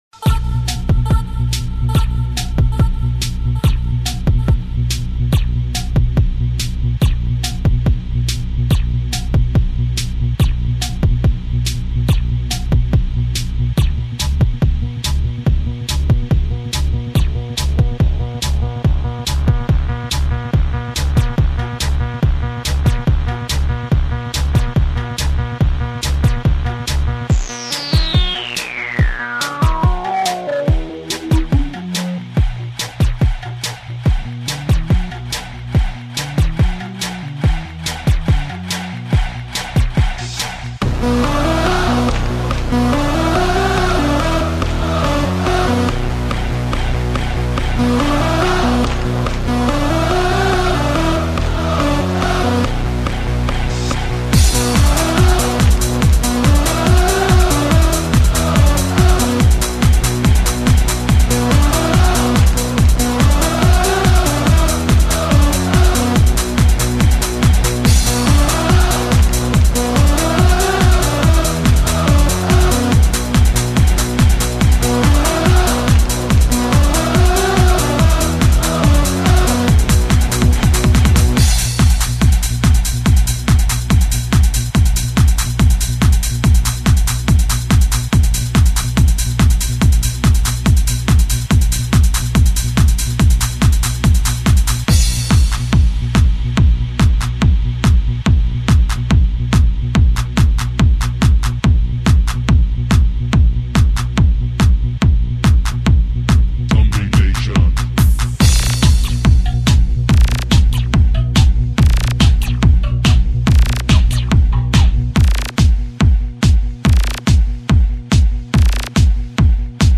(این قطعه فاقد متن ترانه بوده و به صورت بیکلام اجرا شده است)